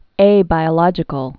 (ābī-ə-lŏjĭ-kəl)